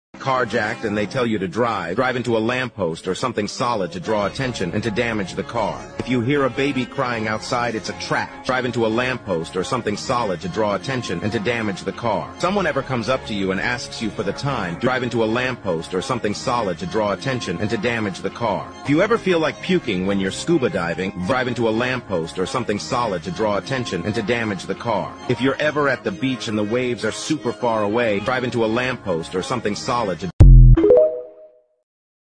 drive into a lampost
drive-into-a-lamppost.mp3